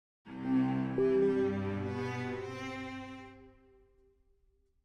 Institucional